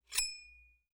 Metal_37.wav